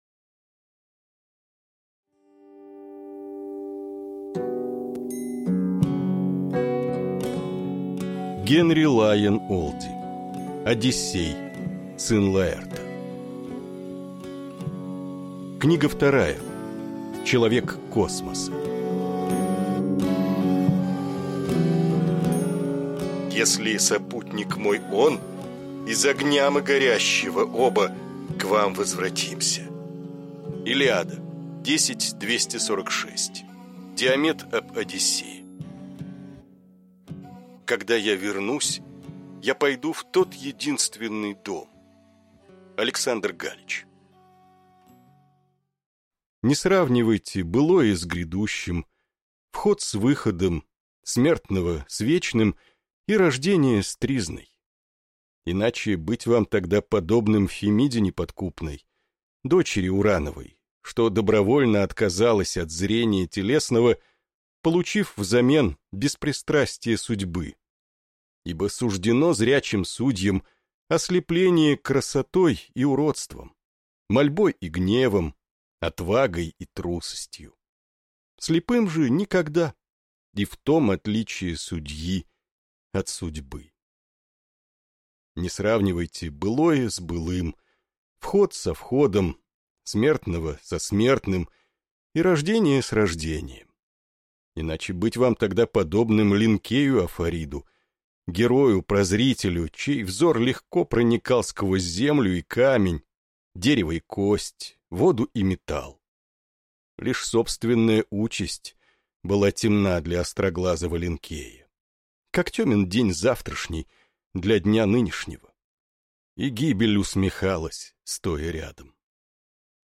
Аудиокнига Одиссей, сын Лаэрта. Человек Космоса | Библиотека аудиокниг